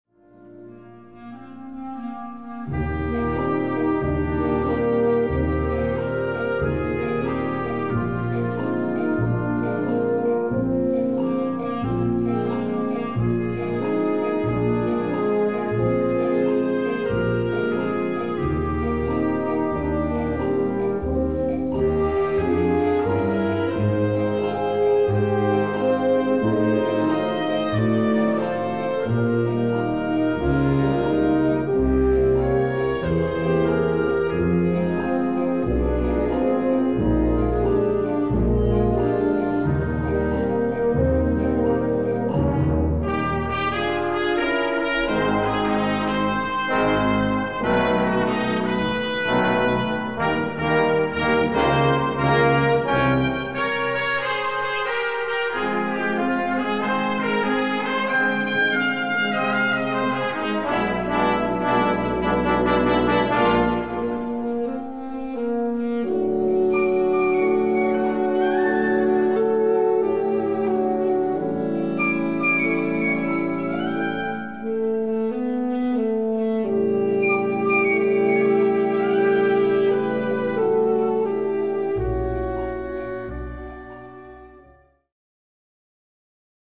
3:00 Minuten Besetzung: Blasorchester Zu hören auf